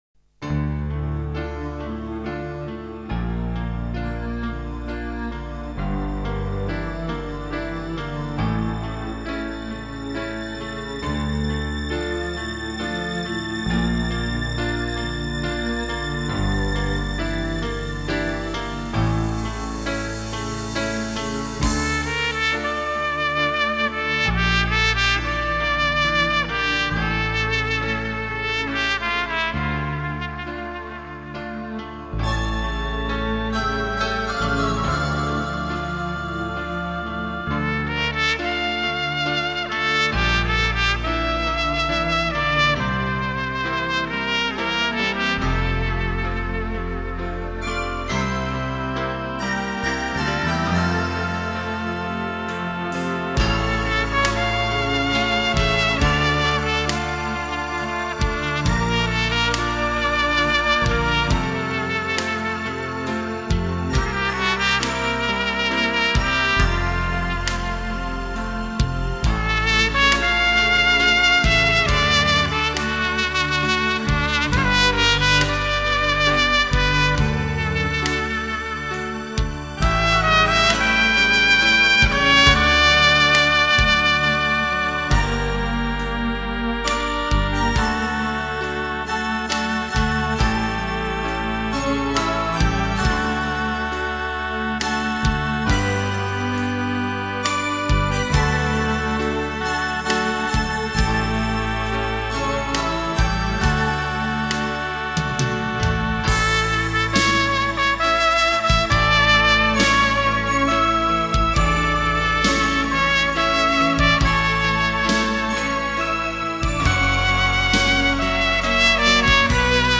欧美浪漫爱情歌曲
以小号浪漫委婉音色诠释激情浩荡的欧美爱情故事